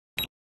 click.aac